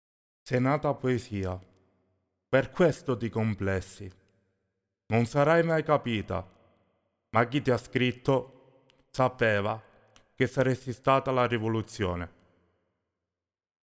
ecco i bianchi (solo voce)